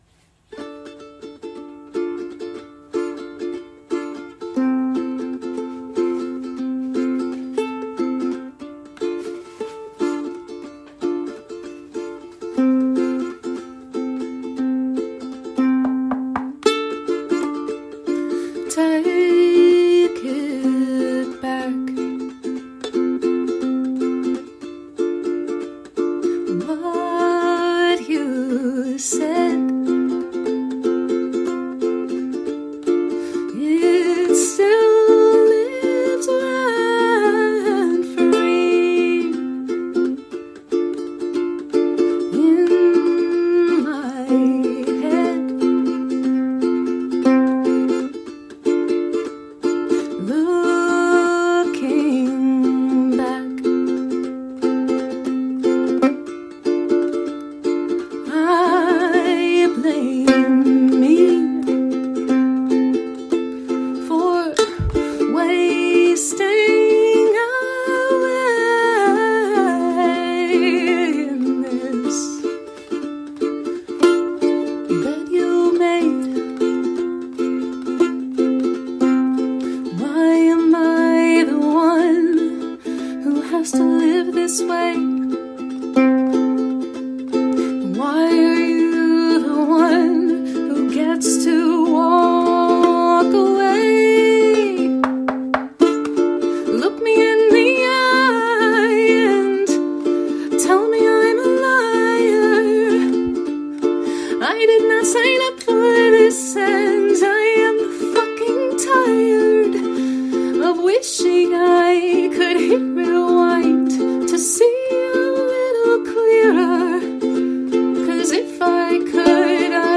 Hand percussion
Good lyric, emotive delivery.